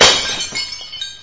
glass.mp3